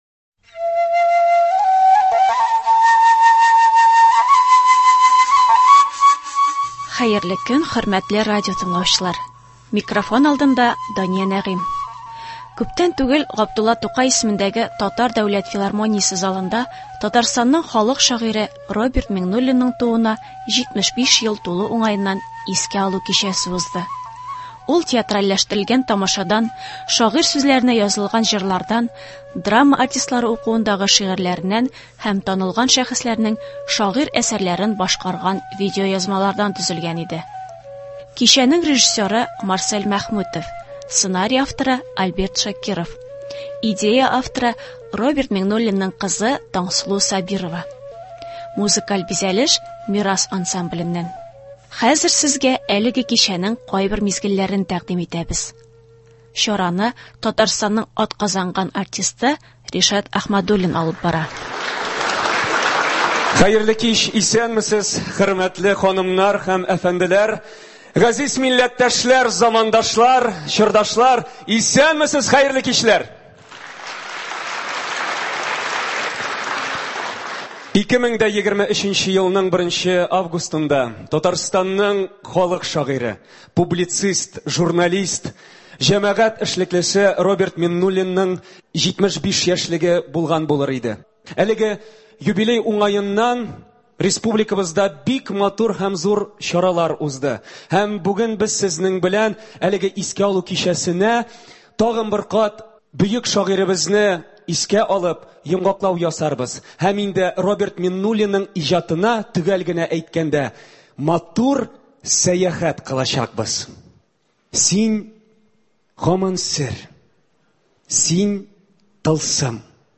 Татарстанның халык шагыйре Роберт Миңнуллинның тууына 75 ел тулу уңаеннан искә алу кичәсеннән репортаж.